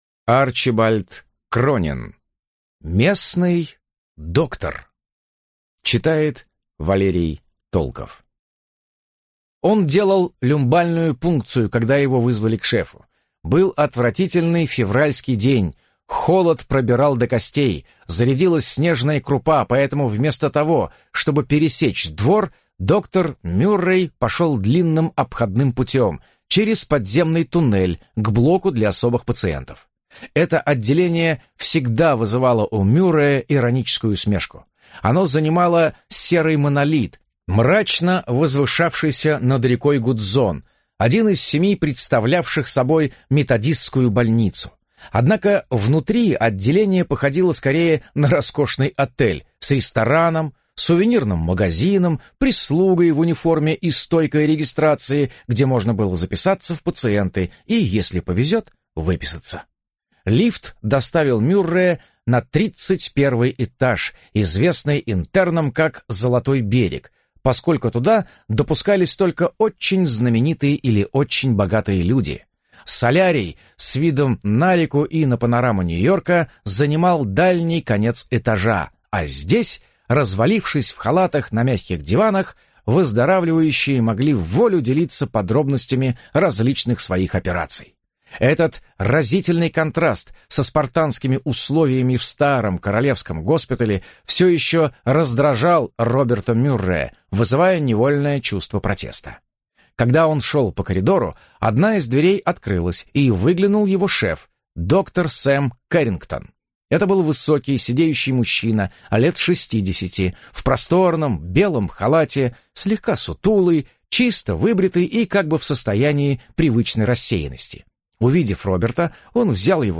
Аудиокнига Местный доктор | Библиотека аудиокниг